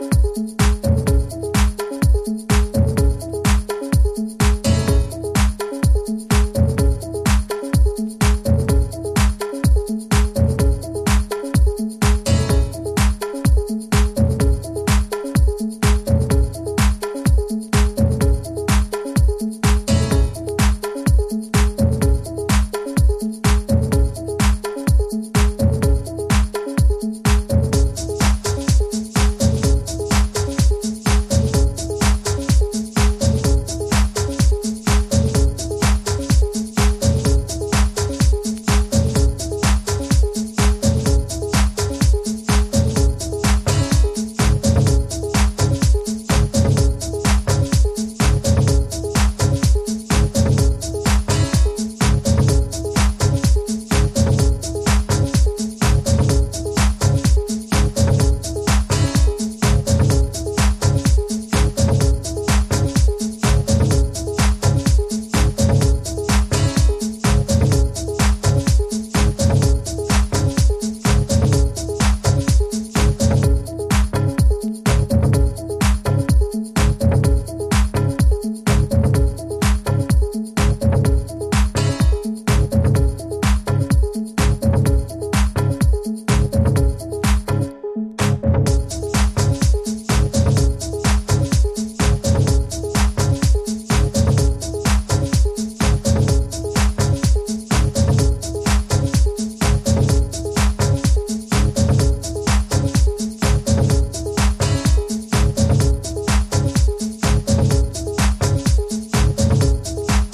House / Techno
ダブテクノ流儀のシンプルなビートとシンセの起伏が心地良いディープテクノ。粒子の粗さも癖になりますね。